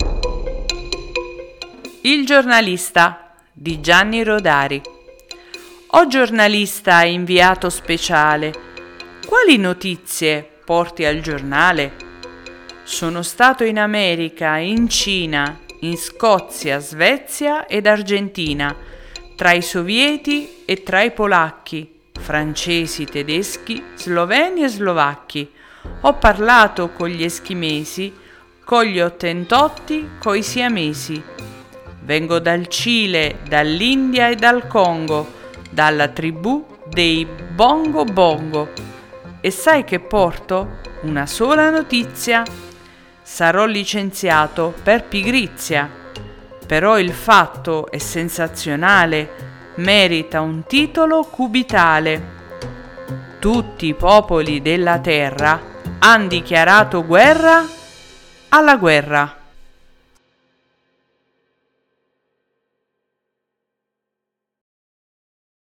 mamma legge la fiaba